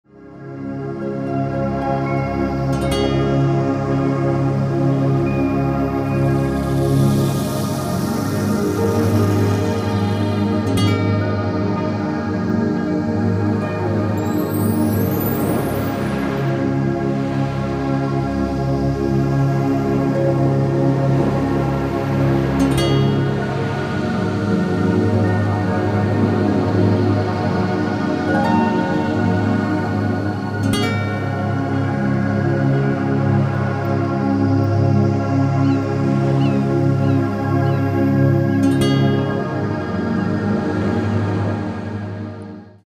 Sound pure   14:41 min